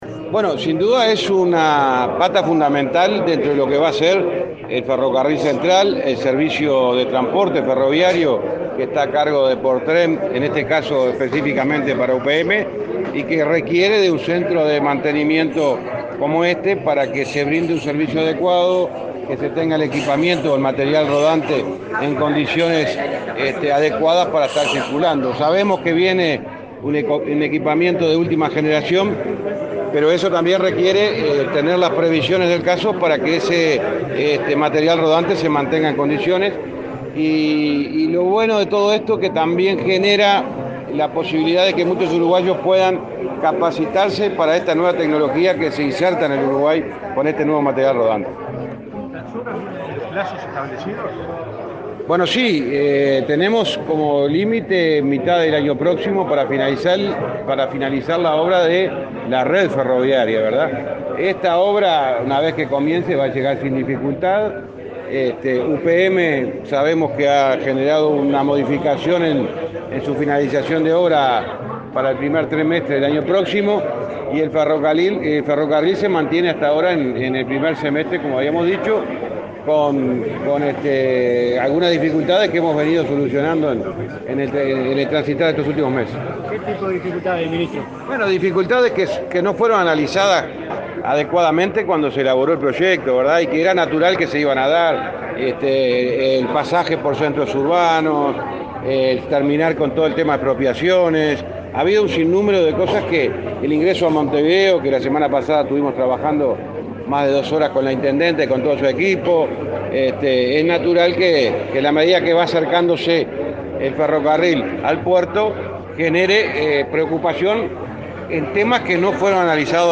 Declaraciones a la prensa del ministro de Transporte
El ministro de Transporte, José Luis Falero, participó este miércoles 16, del acto de inicio de obras del Centro de Operación y Mantenimiento, que
Luego, dialogó con la prensa.